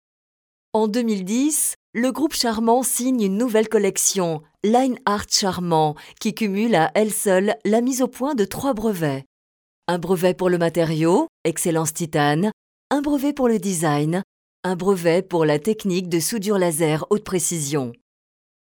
Sprecherin französisch (Muttersprachlerin) warm, smoth and secure, serious, friendly, smilee, fresh intentions
Sprechprobe: Industrie (Muttersprache):
Professional French native voice over: warm, smoth and secure, serious, friendly, smilee, fresh intentions